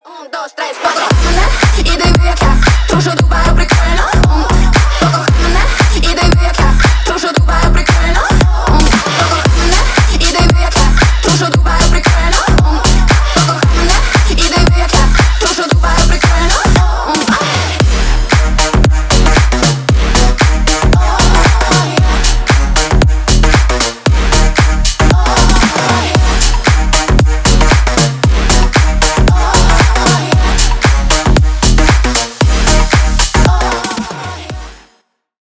клубные